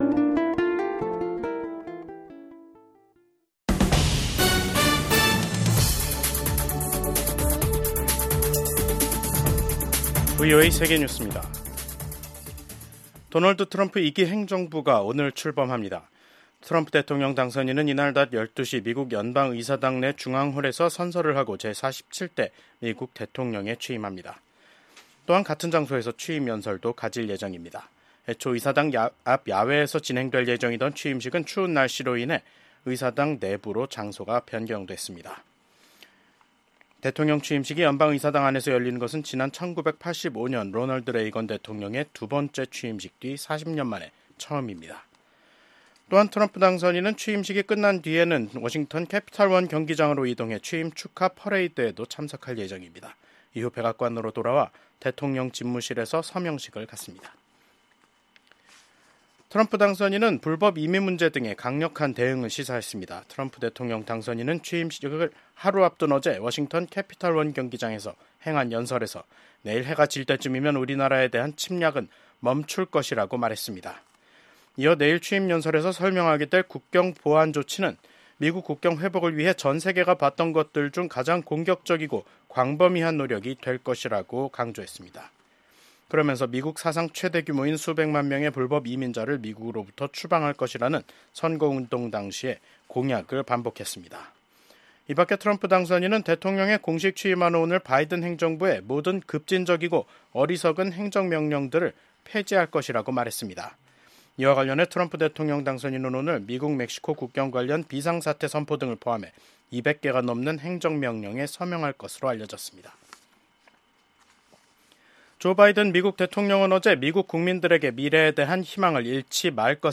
VOA 한국어 간판 뉴스 프로그램 '뉴스 투데이', 2025년 1월 20일 2부 방송입니다. 미국의 제47대 도널드 트럼프 대통령의 취임식 날입니다. VOA한국어 방송의 뉴스투데이는 오늘, 취임식 특집 방송으로 진행합니다.